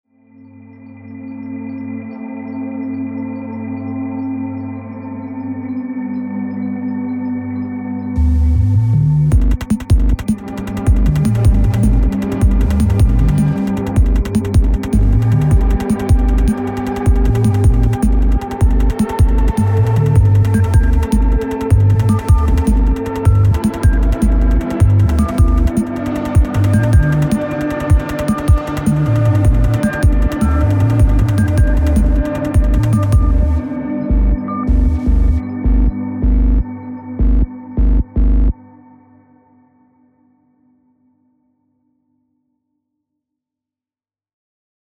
Rhythmical